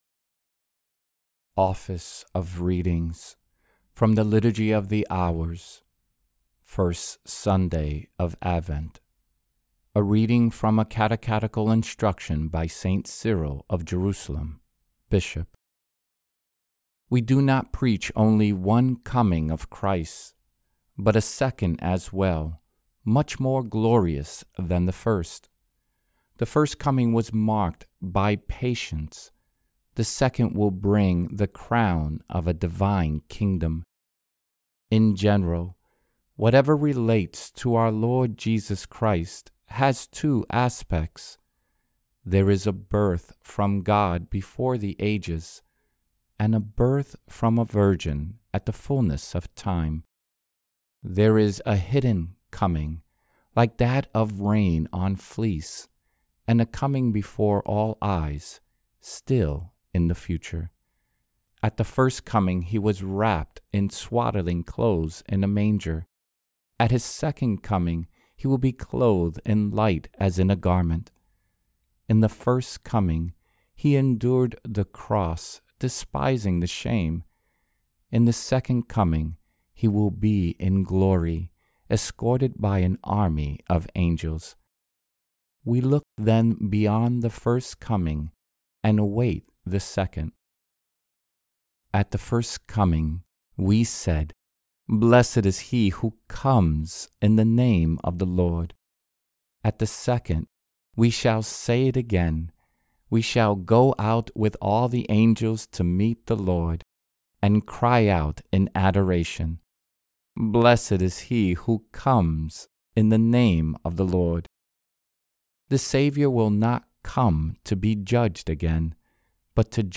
Office of Readings – (1st Sunday of Advent) | Annunciation Catholic Church